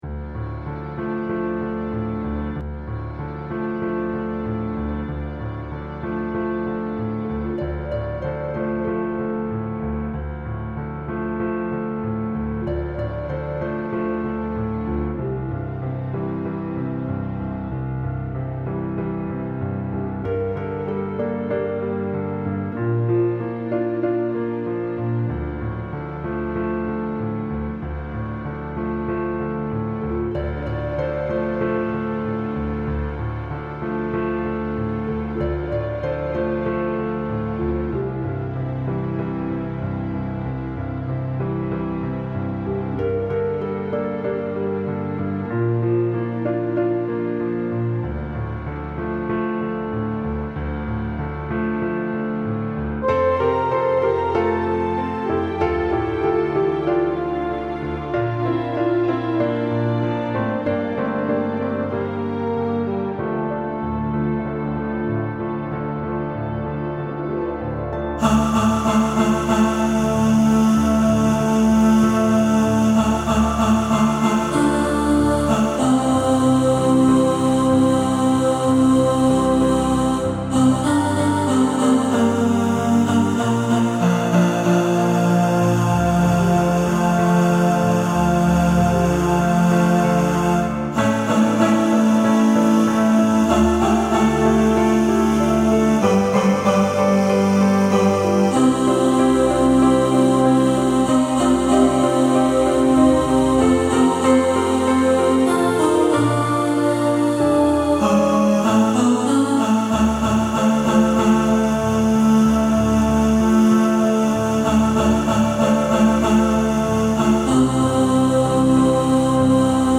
Walking-In-The-Air-Tenor.mp3